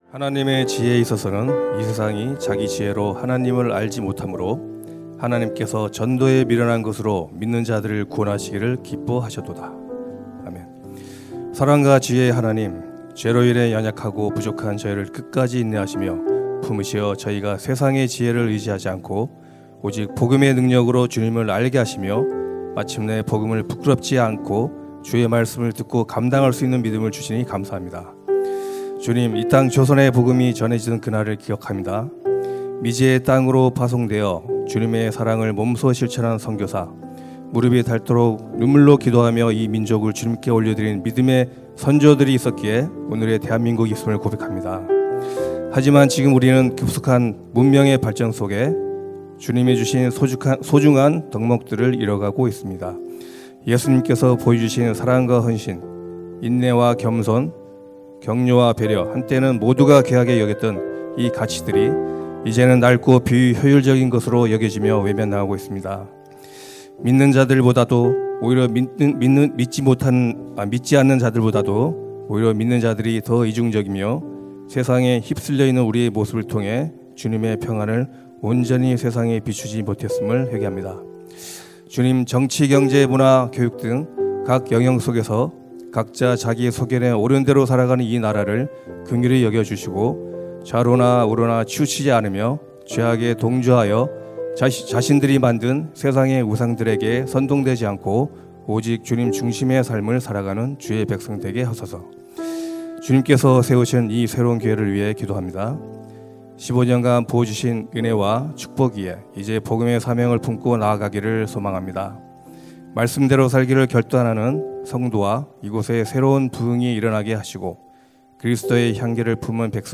[새벽예배]